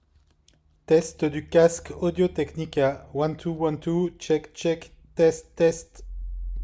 Test du micro du ATH-M50xSTS
Performances du microphone 9